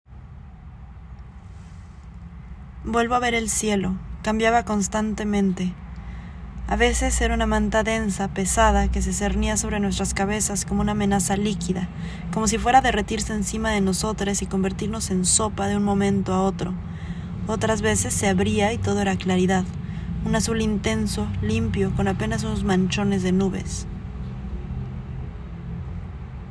Cada fragmento fue escrito como un flujo de conciencia, grabado en audio con mi voz y acompañado por imágenes específicas, evocadas directamente por lo que se cuenta o añadidas por asociación libre.